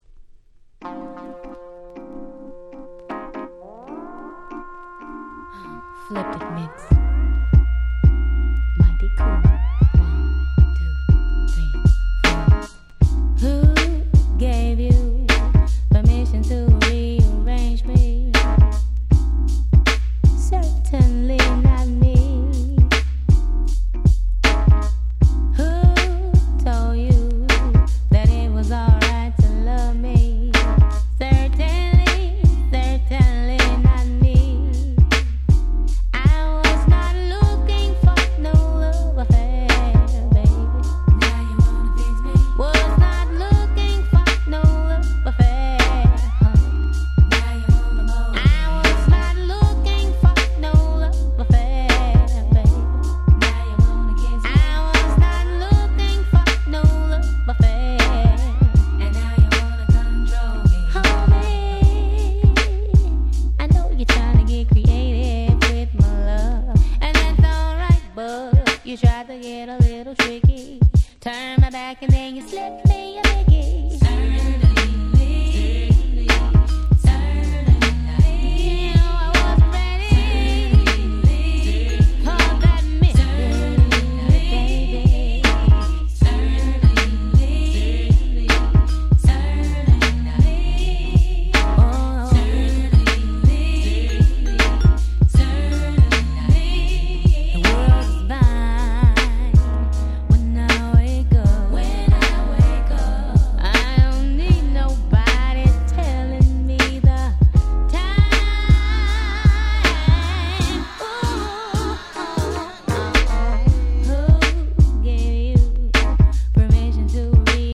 90's ネオソウル ハウス House